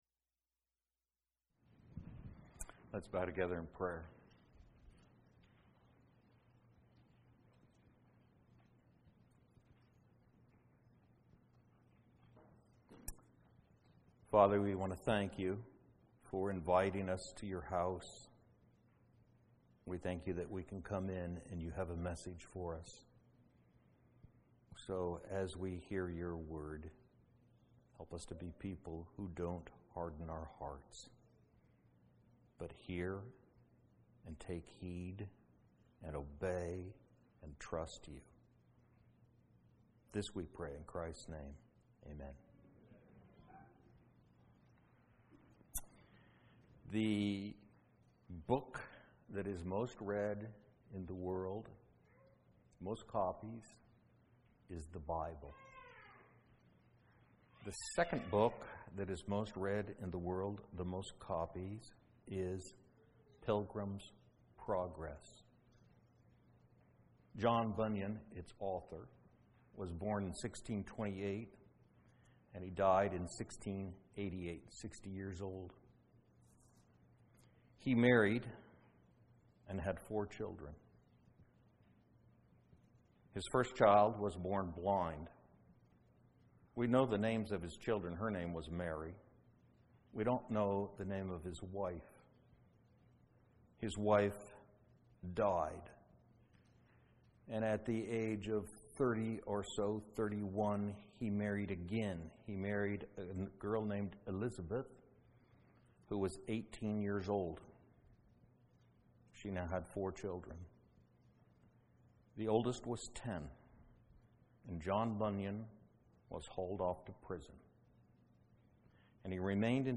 A message from the series "Kingdom Citizenship."